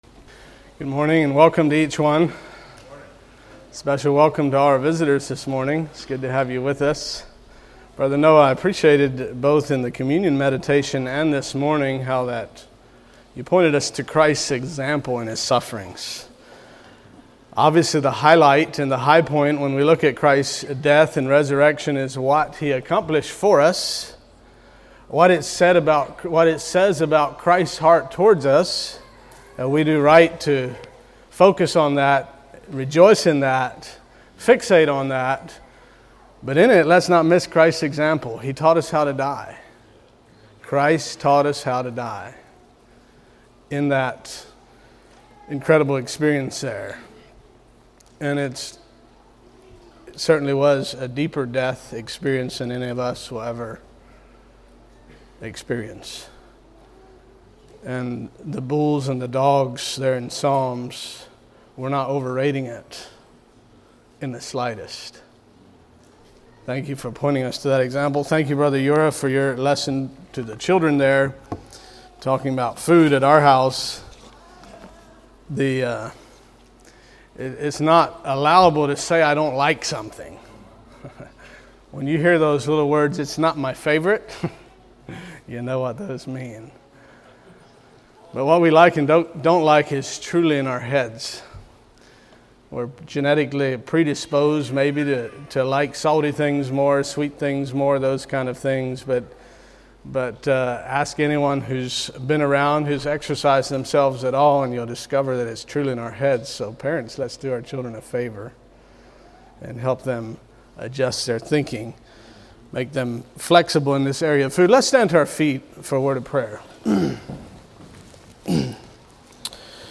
Sermons of 2019 - Blessed Hope Christian Fellowship